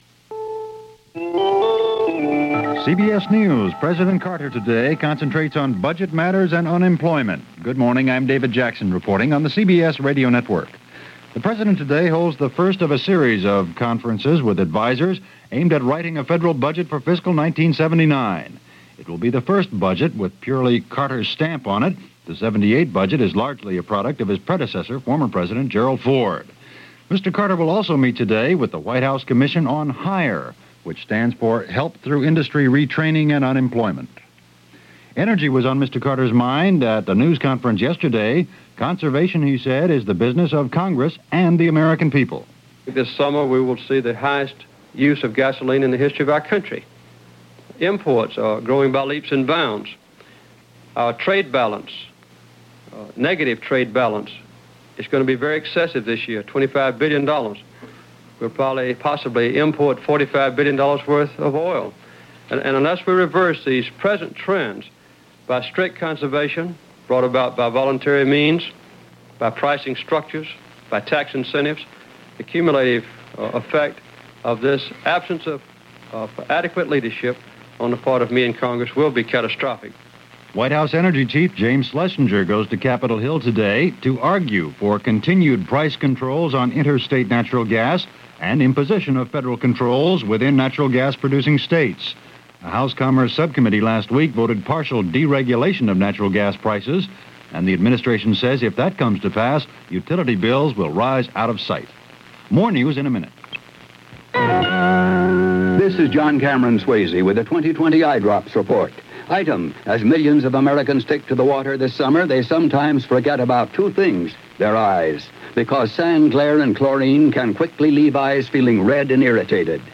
CBS Radio Hourly News